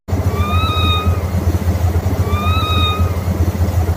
Kura-kura_Suara.ogg